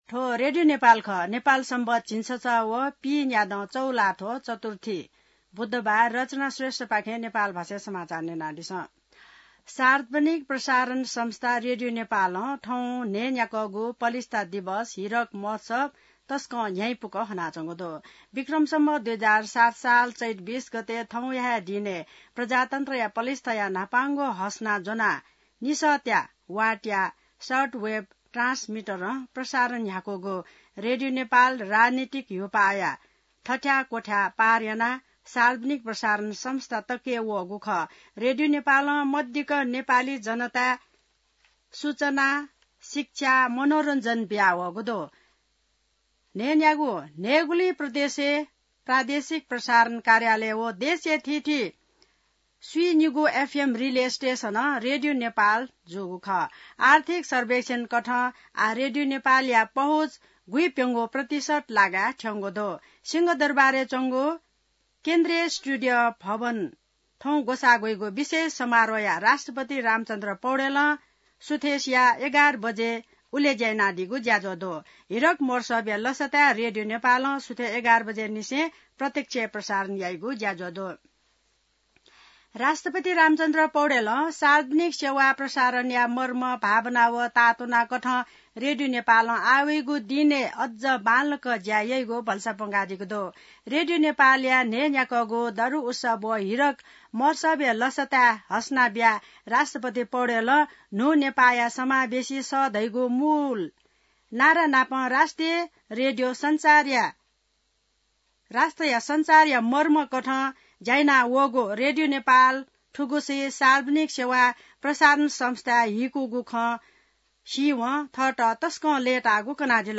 नेपाल भाषामा समाचार : २० चैत , २०८१